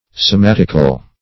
somatical - definition of somatical - synonyms, pronunciation, spelling from Free Dictionary Search Result for " somatical" : The Collaborative International Dictionary of English v.0.48: Somatical \So*mat"ic*al\, a. Somatic.